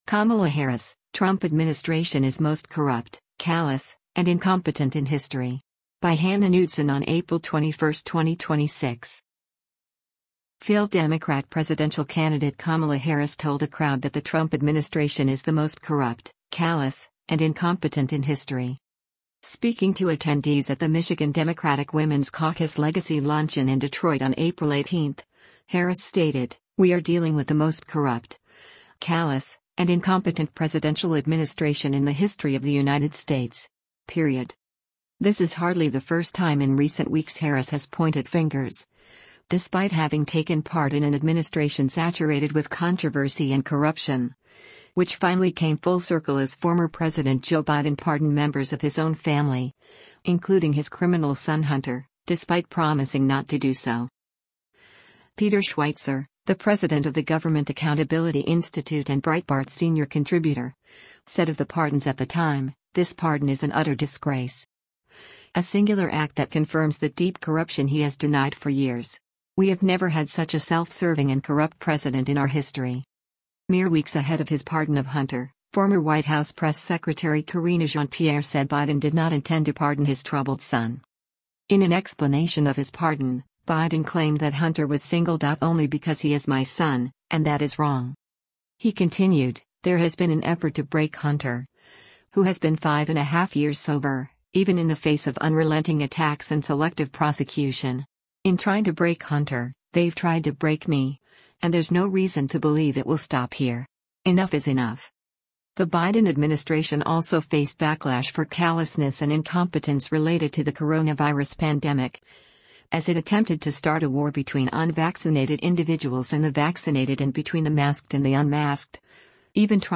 Failed Democrat presidential candidate Kamala Harris told a crowd that the Trump administration is the most “corrupt, callous, and incompetent in history.”
Speaking to attendees at the Michigan Democratic Women’s Caucus Legacy Luncheon in Detroit on April 18, Harris stated, “We are dealing with the most corrupt, callous, and incompetent presidential administration in the history of the United States. Period.”